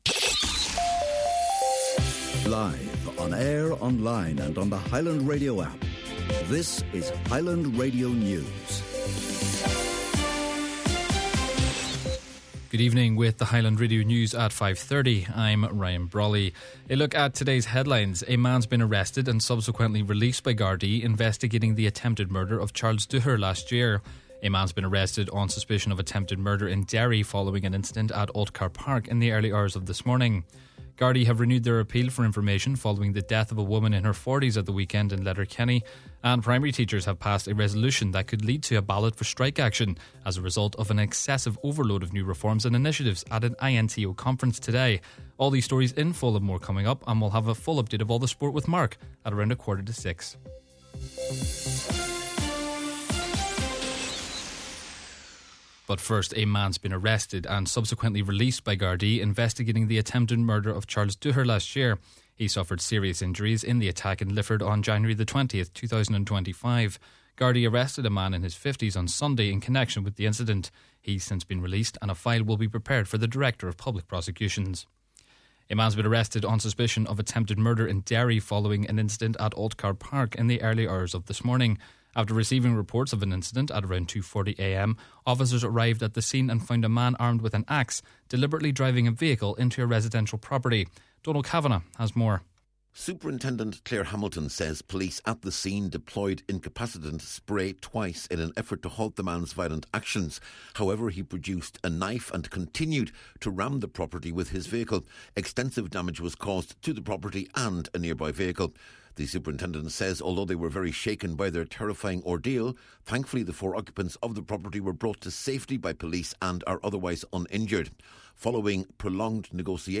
Main Evening News, Sport and Obituary Notices – Tuesday, April 7th